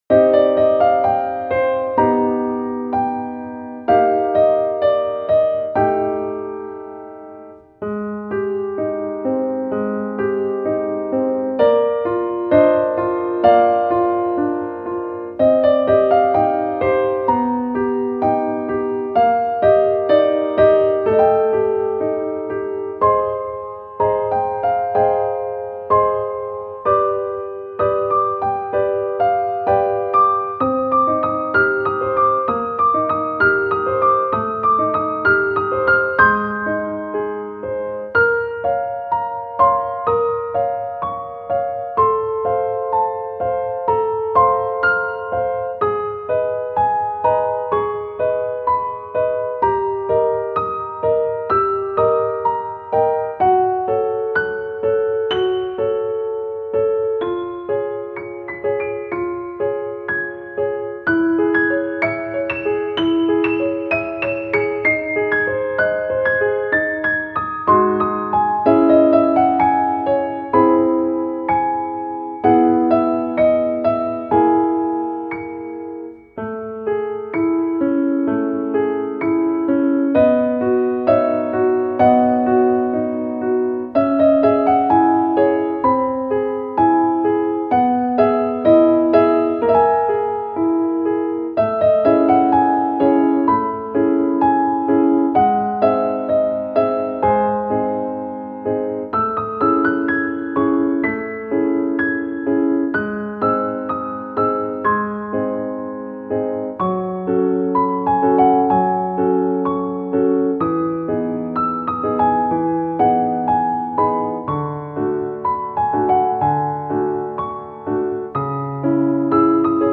-oggをループ化-   しっとり アンニュイ 2:10 mp3